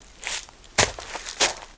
High Quality Footsteps / Leaves / Misc / MISC Leaves, Foot Scrape 05.wav
MISC Leaves, Foot Scrape 05.wav